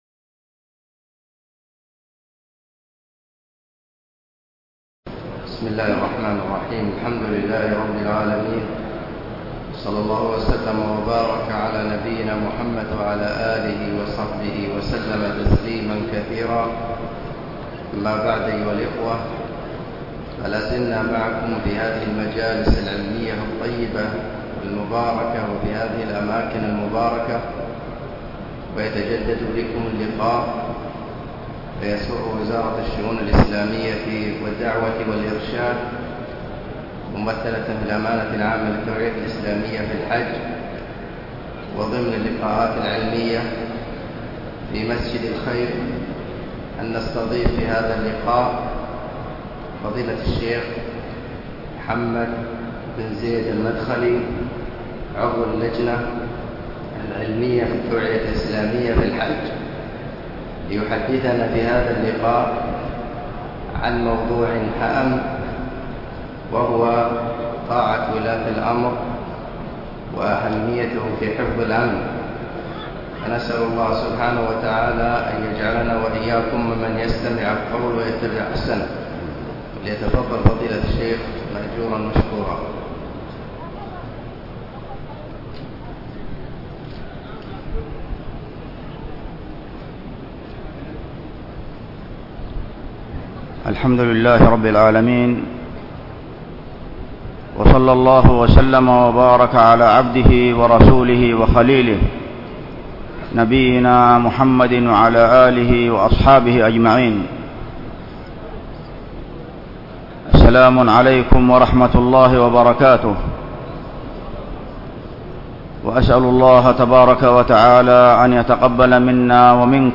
مواعظ ورقائق